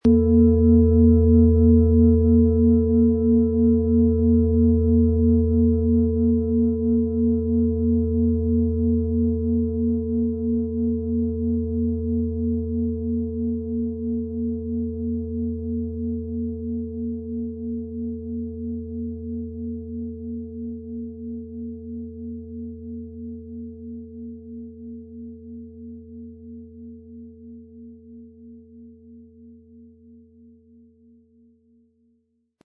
Hopi Herzton
Diese tibetische Klangschale mit dem Ton von Hopi-Herzton wurde von Hand gearbeitet.
• Mittlerer Ton: Mond
Wie klingt diese tibetische Klangschale mit dem Planetenton Hopi-Herzton?
Im Sound-Player - Jetzt reinhören können Sie den Original-Ton genau dieser Schale anhören.
MaterialBronze